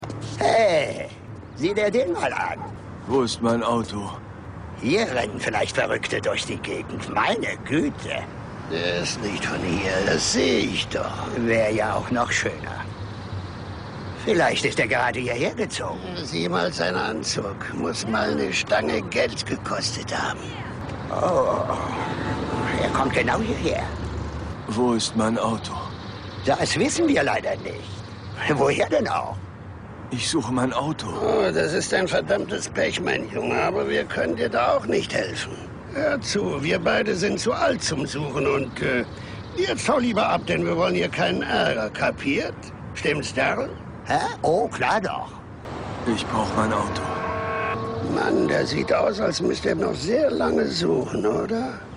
Leute, da reden zwei alte Herren miteinander